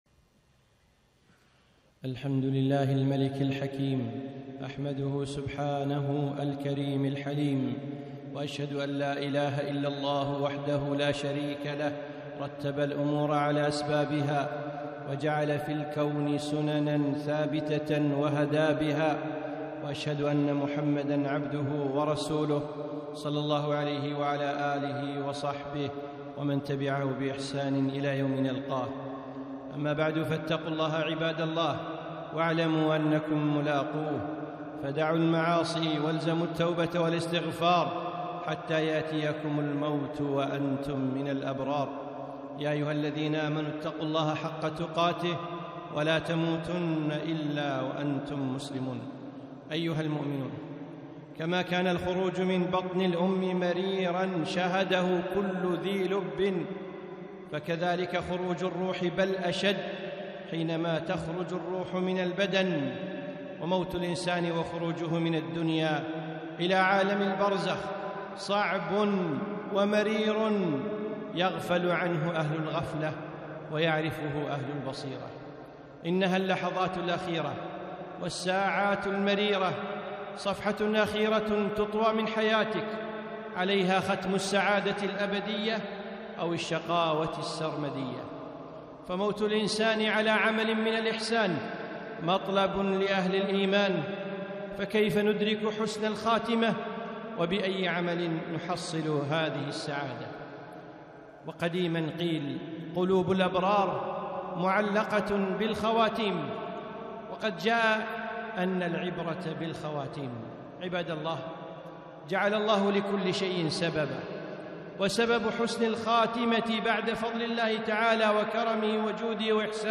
خطبة - أسباب حسن الخاتمة